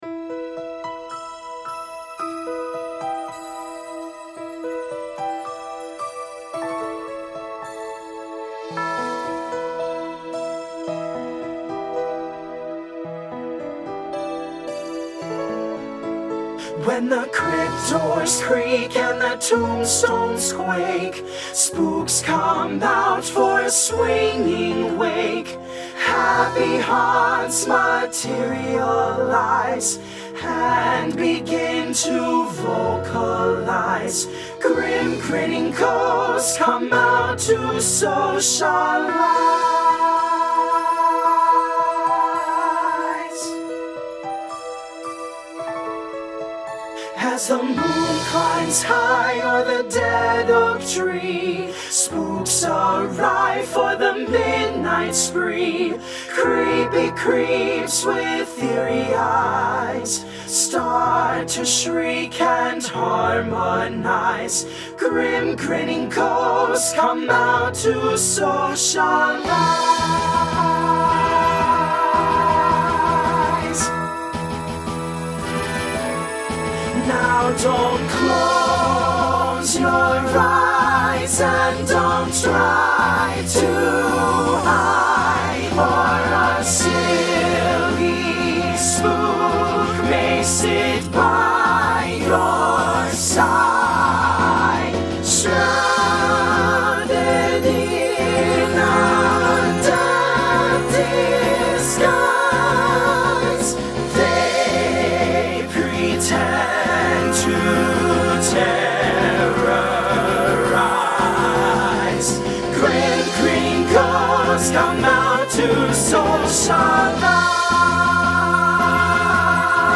Voicing SATB Instrumental combo Genre Broadway/Film
Ballad